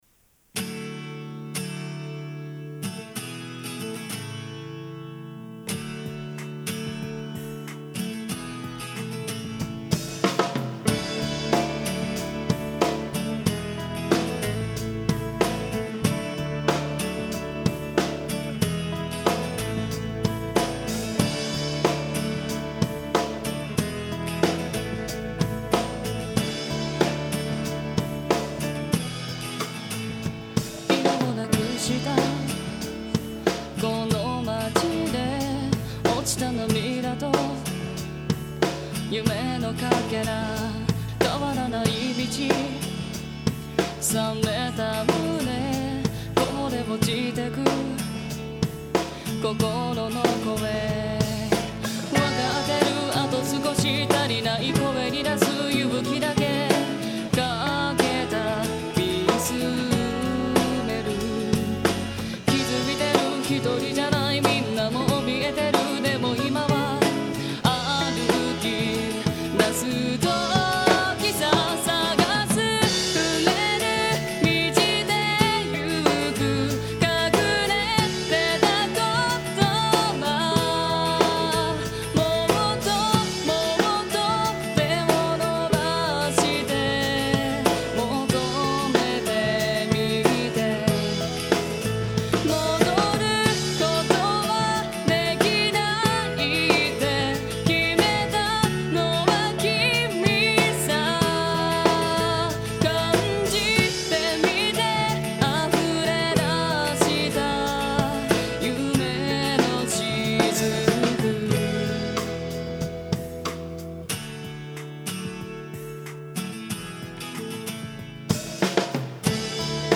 この音源は私にとって初めてのレコーディング。
私の原点となったこの曲を私なりに素直に歌っています。